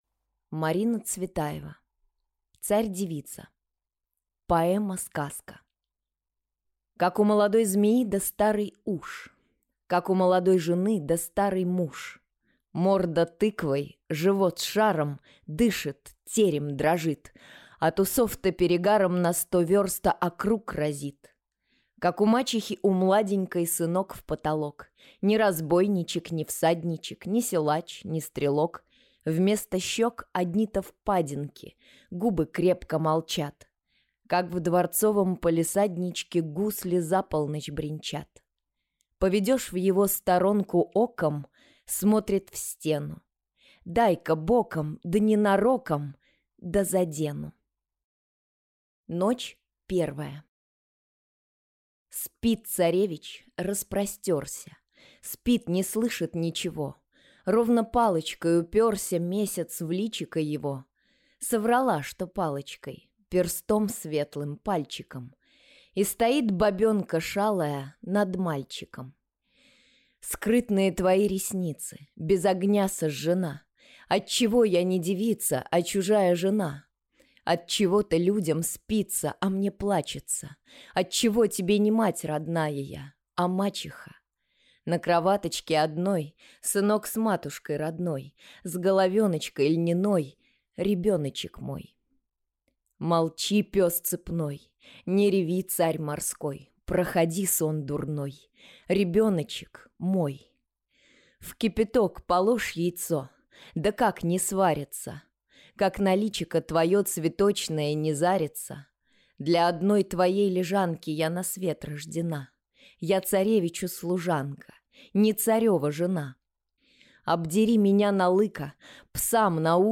Аудиокнига Царь-девица | Библиотека аудиокниг